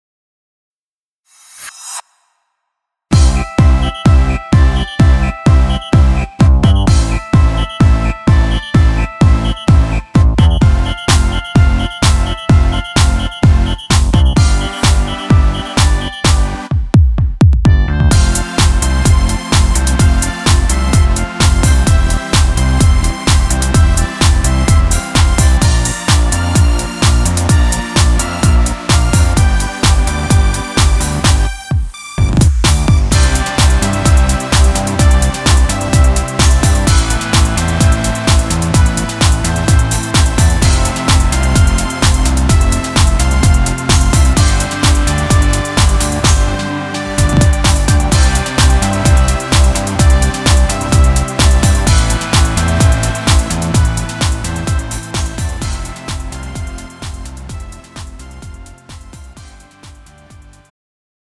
음정 원키 3:15
장르 가요 구분 Pro MR